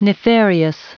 Prononciation du mot nefarious en anglais (fichier audio)
Prononciation du mot : nefarious